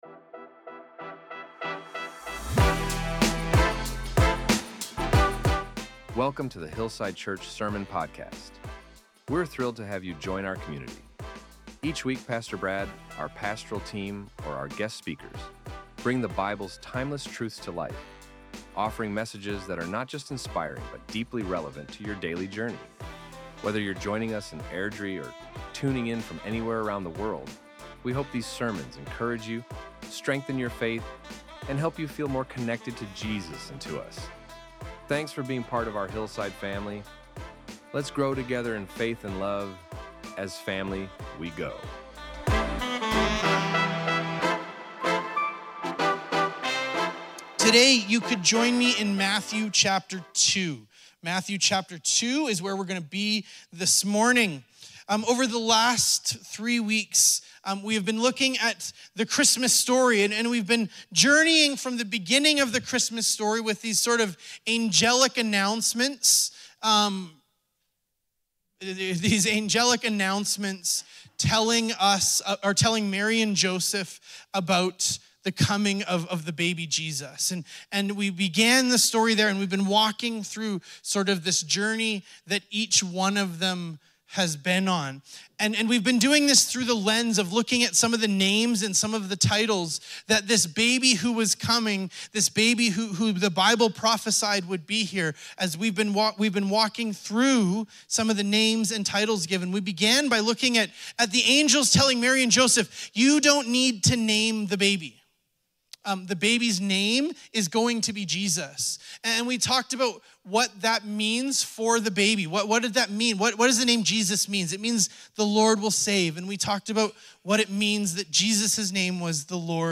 Our Sermons | HILLSIDE CHURCH